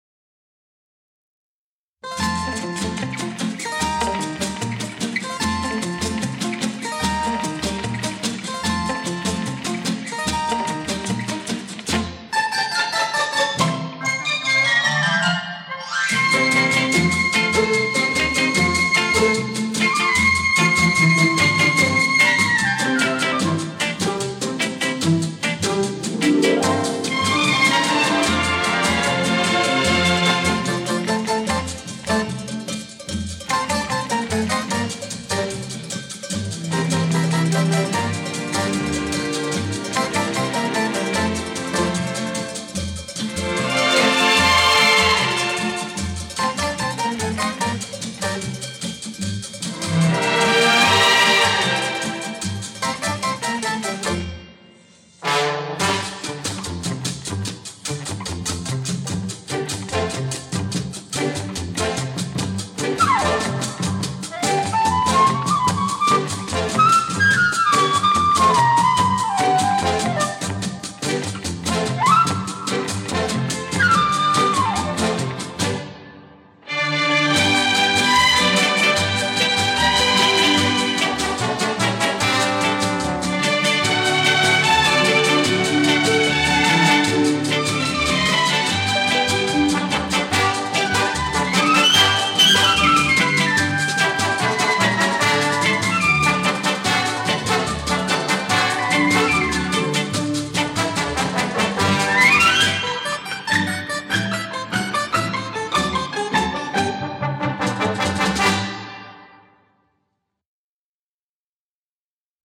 Genre:Instrumental,Easy Listening